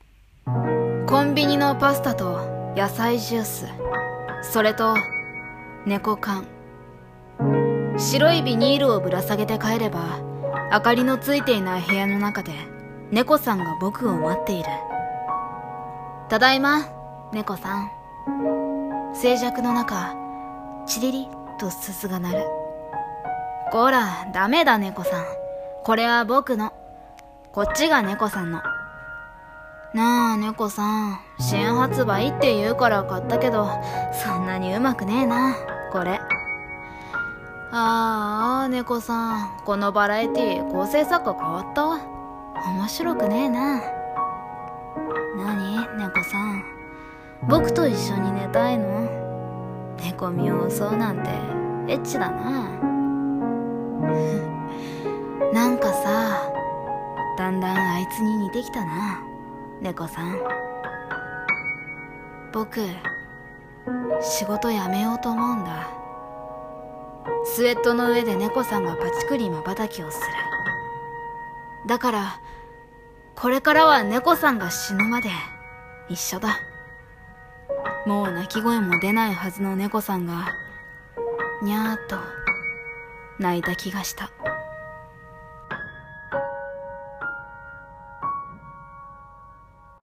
【一人声劇】さよならネコさん